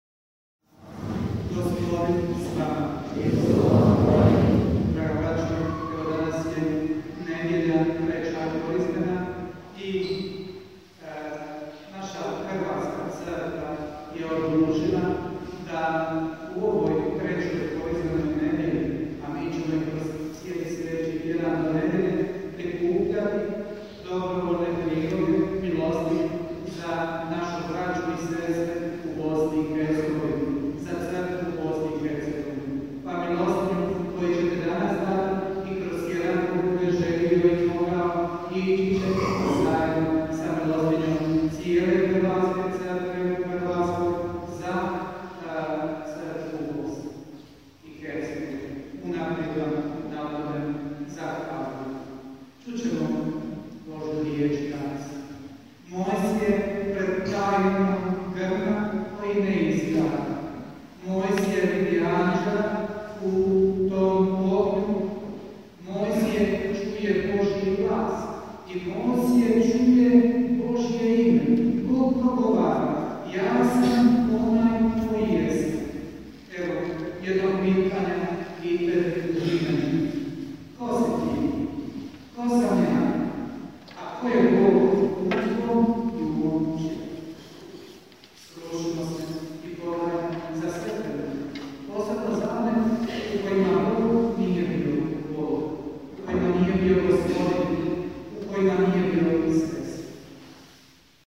POČETAK sv. MISE
UVOD u misno slavlje – 3KNC 2016